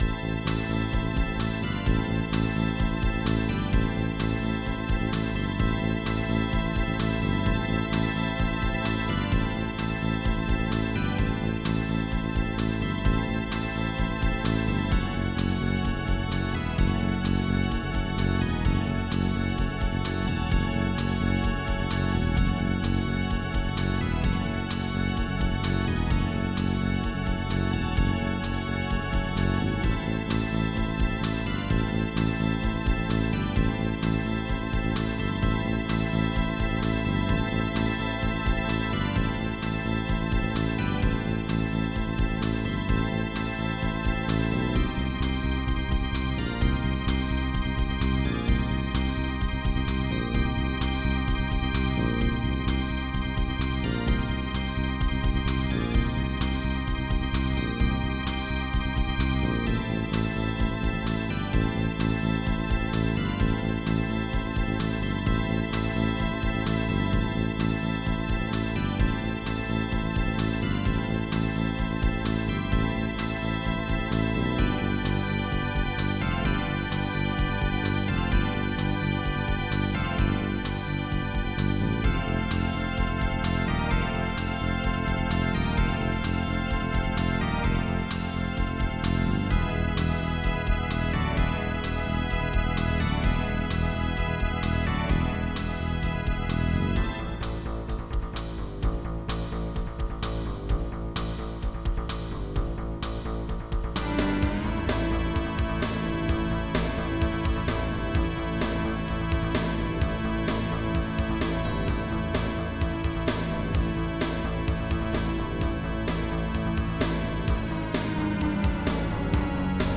Extended MIDI